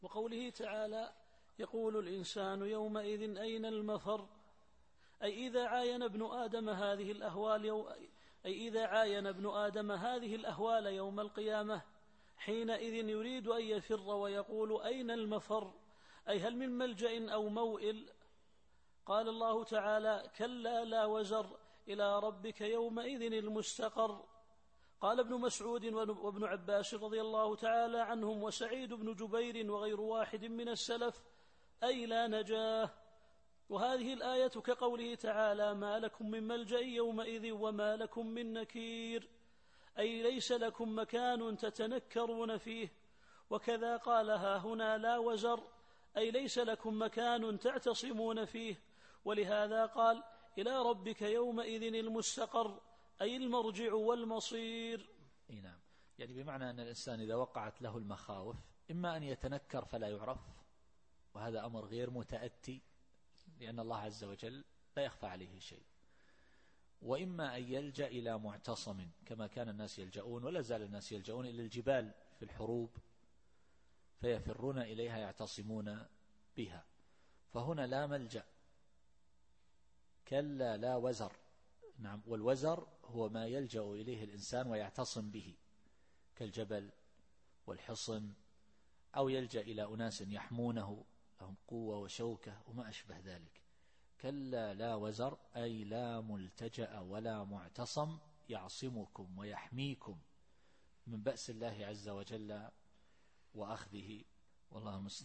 التفسير الصوتي [القيامة / 12]